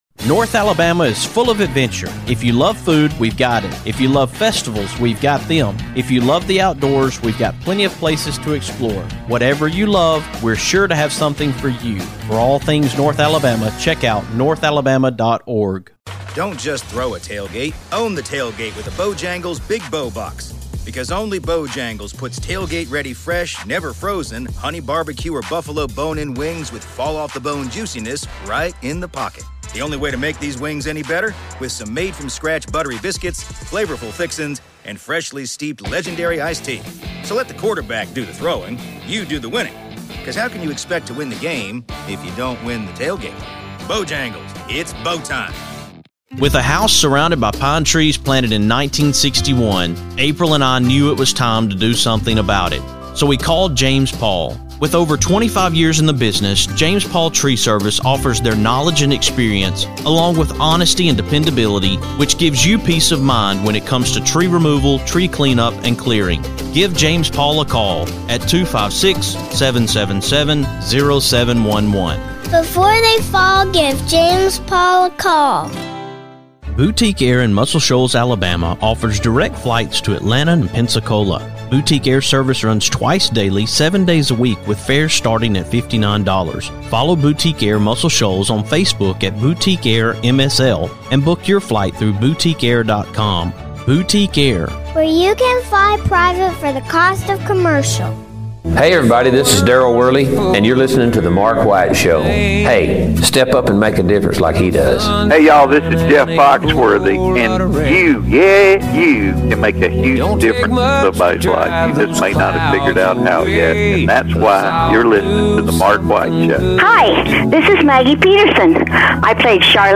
On tonight’s show, I’m coming to you from Moulton, Alabama, at the Lawrence County Alabama Chamber of Commerce!